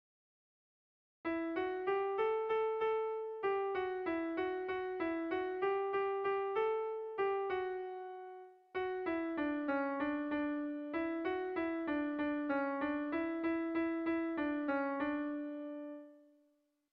Erlijiozkoa
Ziordia < Sakana < Iruñeko Merindadea < Nafarroa < Euskal Herria
Lauko handia (hg) / Bi puntuko handia (ip)
AB